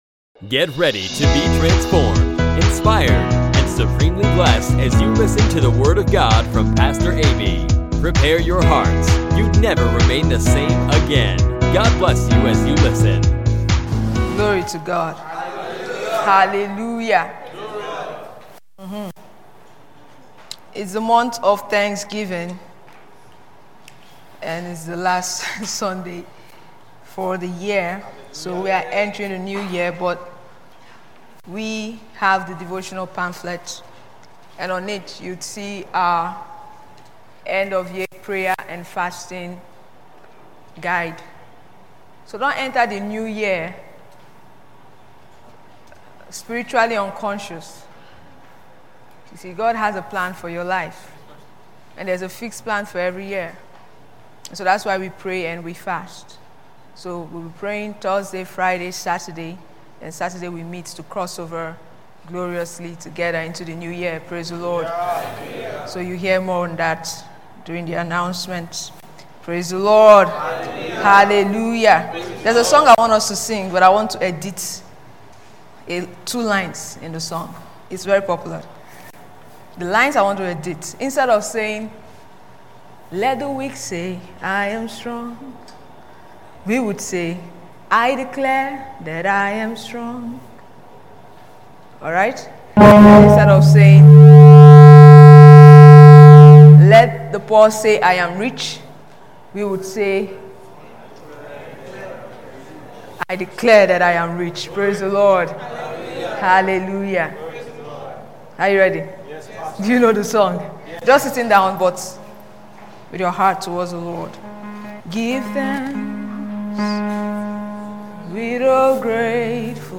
Learn more in this insightful sermon by Pastor.